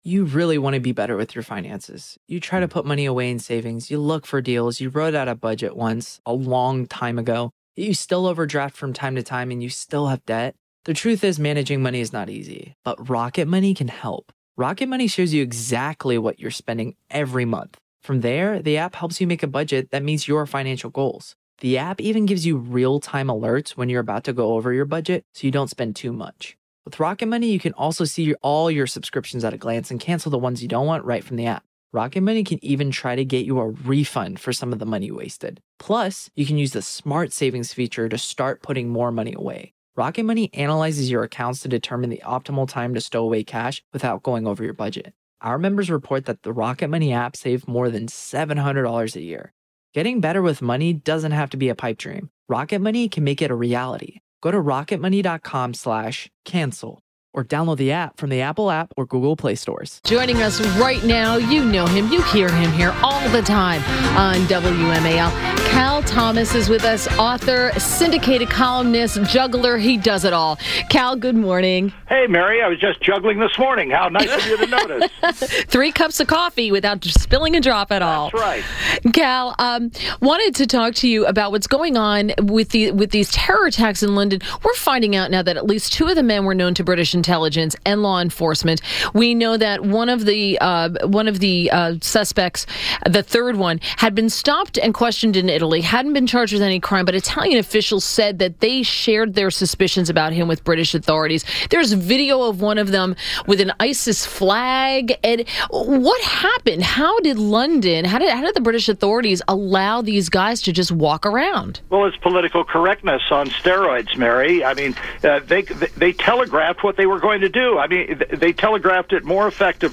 WMAL Interview - CAL THOMAS 06.06.17